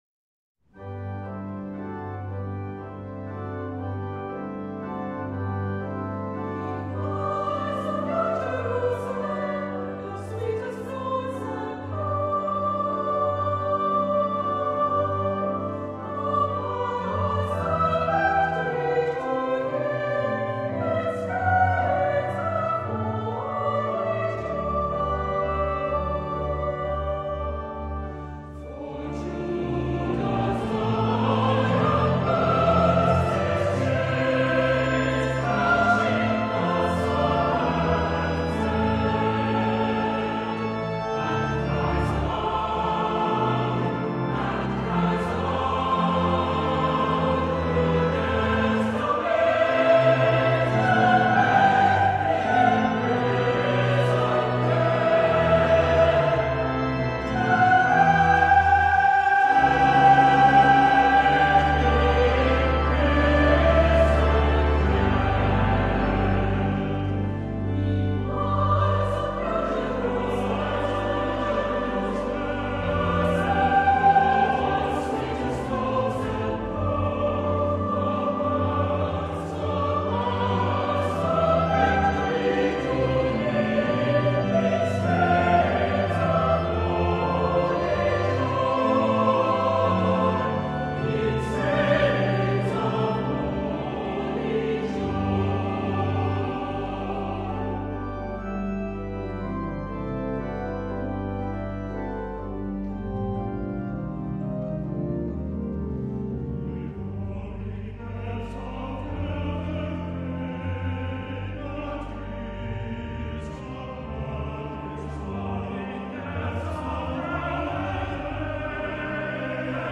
As shown in the photo, in 2019 the Cathedral Choir enjoyed a one-week performance residency at Canterbury Cathedral, the Mother Church of the worldwide Anglican Communion and seat of the Archbishop of Canterbury.